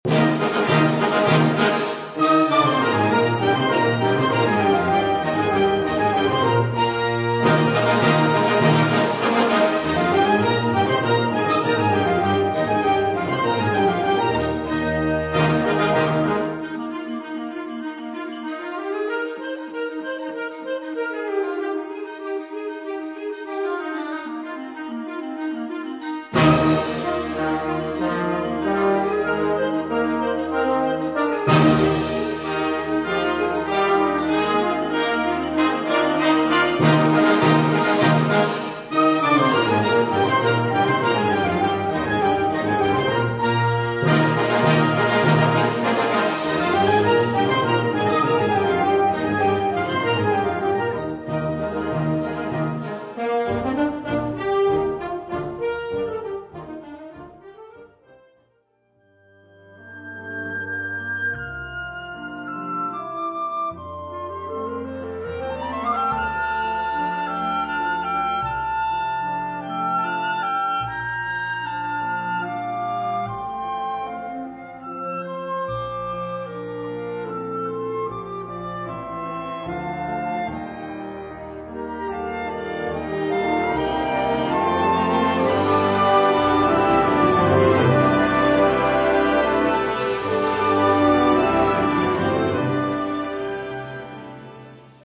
Subcategorie Concertmuziek
Bezetting Ha (harmonieorkest)